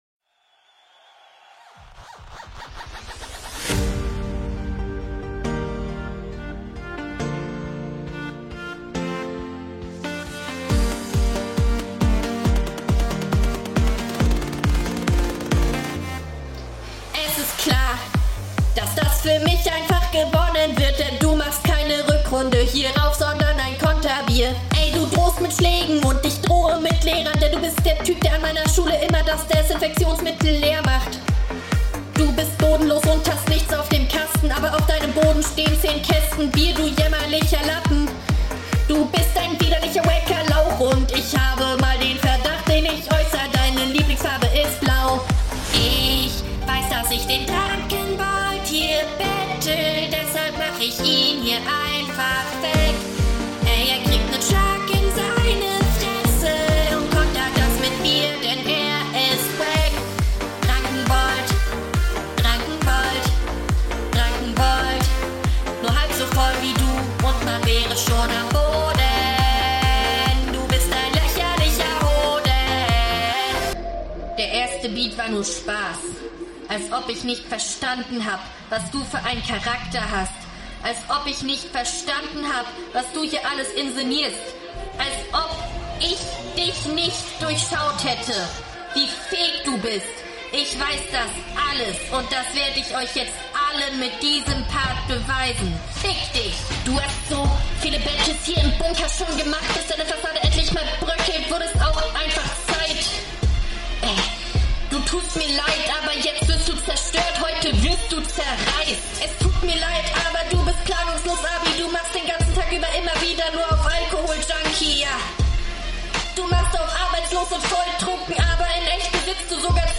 Ey du kannst echt singen.